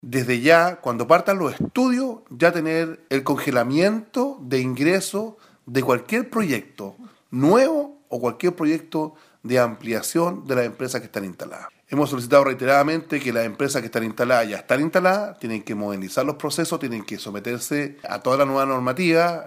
cu-uso-de-suelo-alcalde.mp3